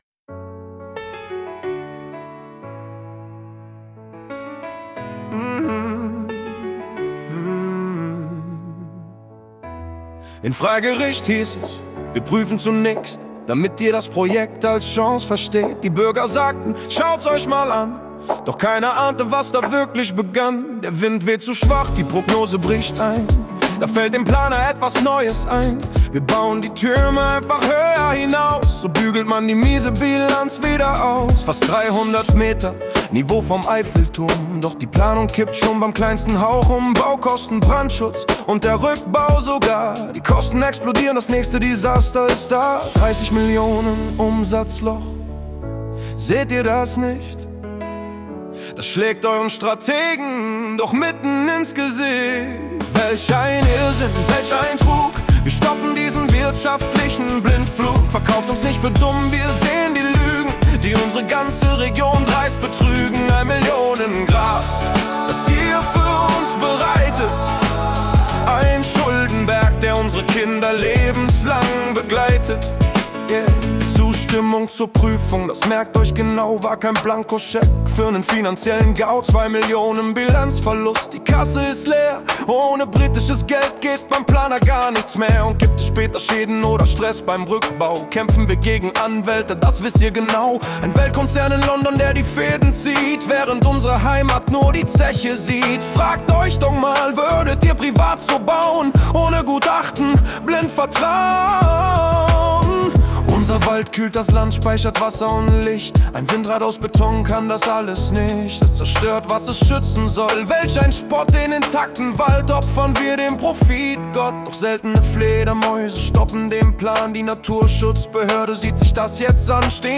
Ein Lied war auch dabei
Speziell für diese Informationsveranstaltung wurde das Lied „Ein Millionen-Grab“ erstellt und abgespielt.
„Ein Millionen-Grab“ | Text: Gegenwind Freigericht e.V. | Musik: Die musikalische Umsetzung wurde mit einer KI erstellt.